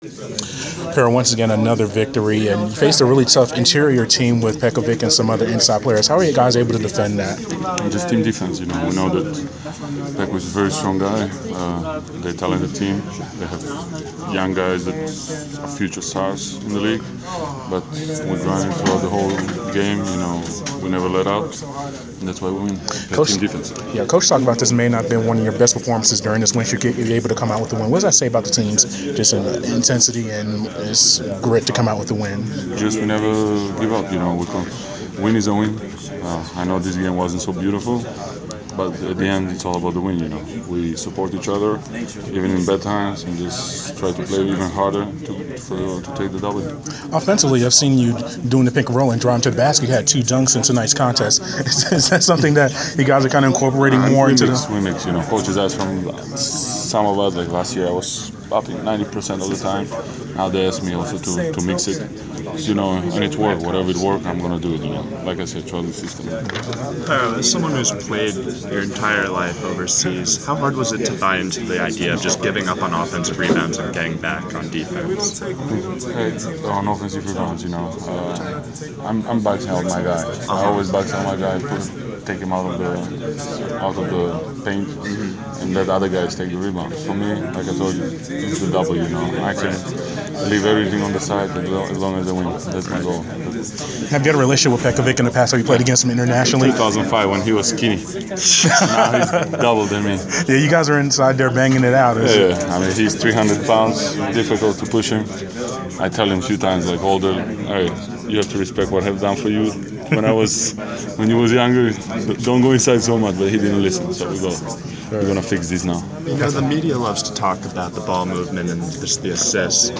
Inside the Inquirer: Postgame interview with Atlanta Hawks’ Pero Antic (1/25/15)
We attended the postgame presser of Atlanta Hawks’ forward Pero Antic following his team’s 112-100 home win over the Minnesota Timberwolves on Jan. 25.